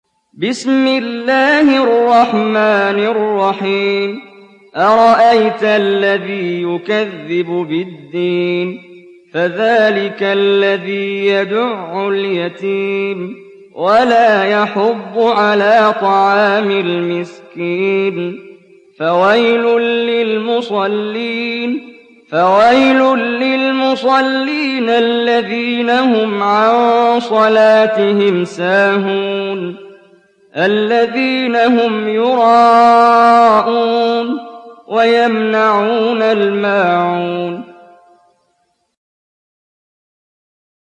সূরা আল-মা‘ঊন ডাউনলোড mp3 Muhammad Jibreel উপন্যাস Hafs থেকে Asim, ডাউনলোড করুন এবং কুরআন শুনুন mp3 সম্পূর্ণ সরাসরি লিঙ্ক